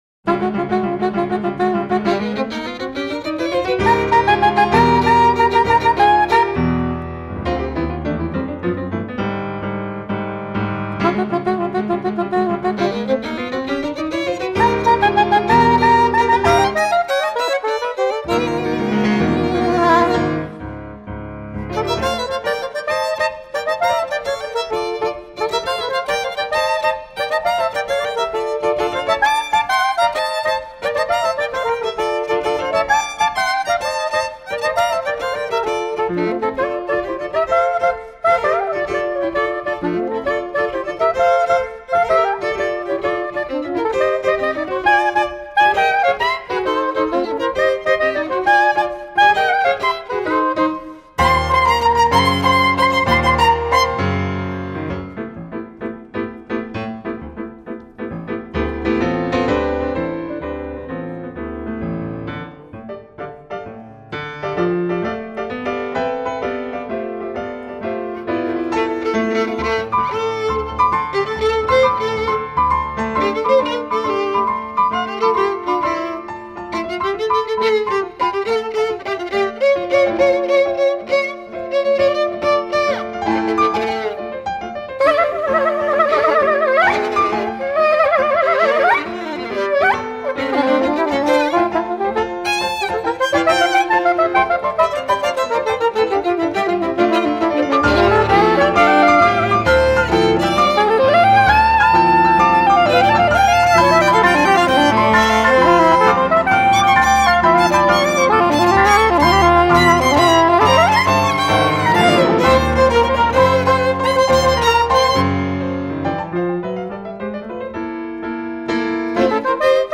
Chamber jazz.
piano
soprano saxophone